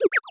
Water3.wav